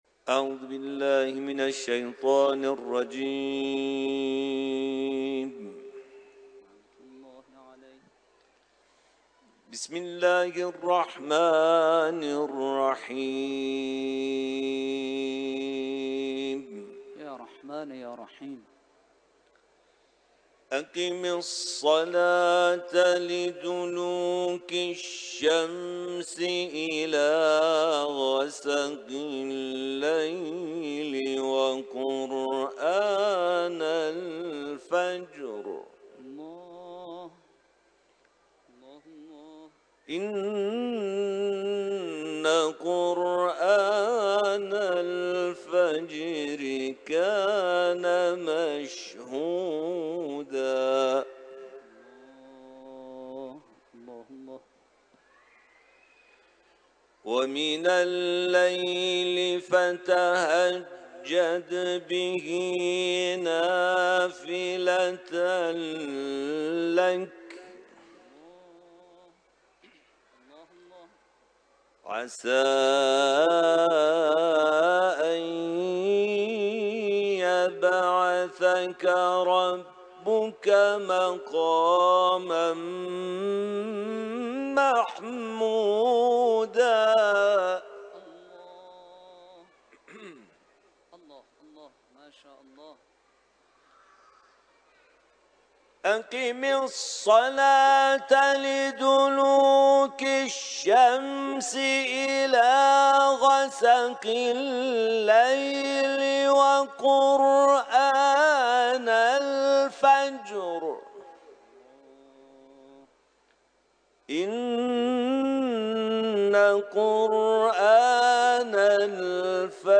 سوره اسرا ، تلاوت قرآن